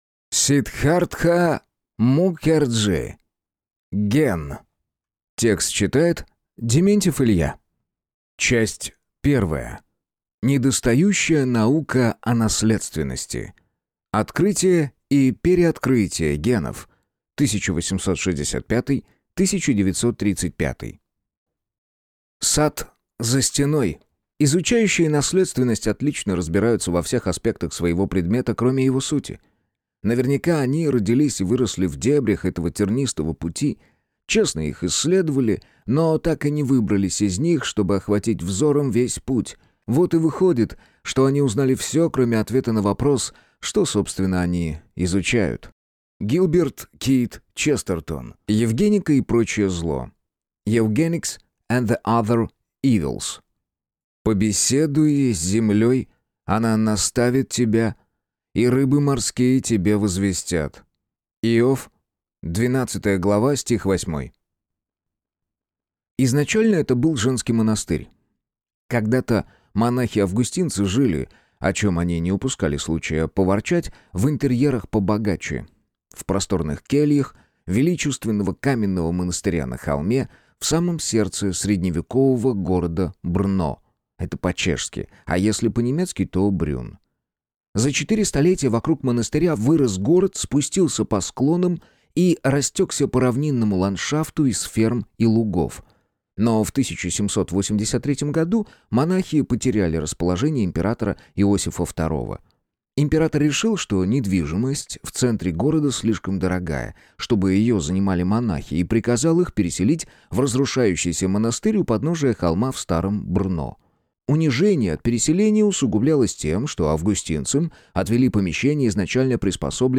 Аудиокнига Ген. Часть 1 | Библиотека аудиокниг